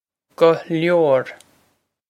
Pronunciation for how to say
guh lyore
This is an approximate phonetic pronunciation of the phrase.